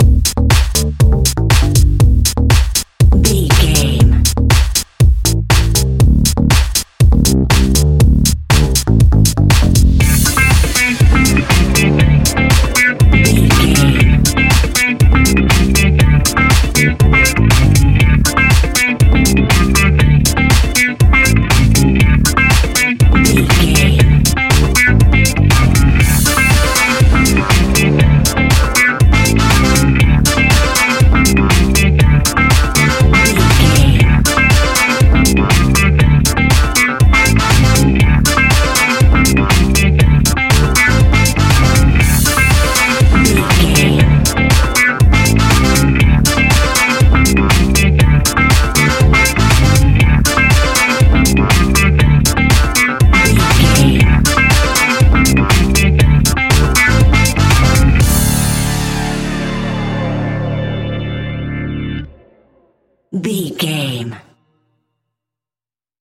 Aeolian/Minor
D
groovy
futuristic
hypnotic
uplifting
bass guitar
electric guitar
drums
synthesiser
funky house
disco house
electro funk
energetic
upbeat
synth leads
Synth Pads
synth bass
drum machines